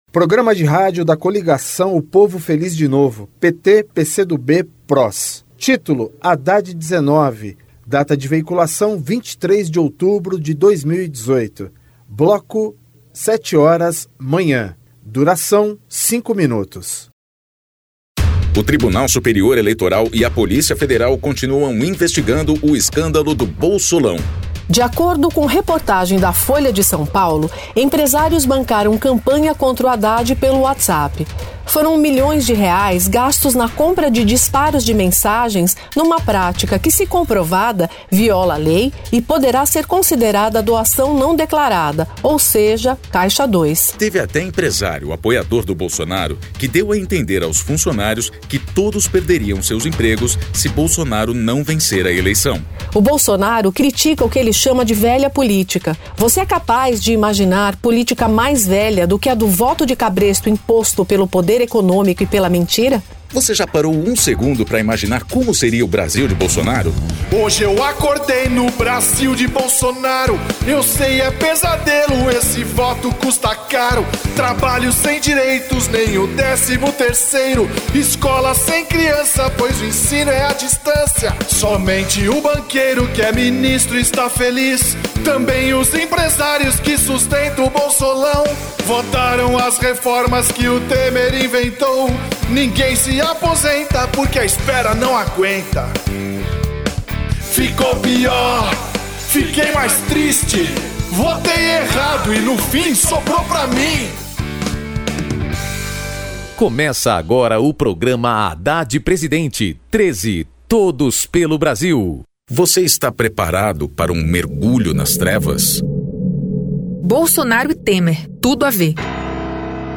TítuloPrograma de rádio da campanha de 2018 (edição 49)
Gênero documentaldocumento sonoro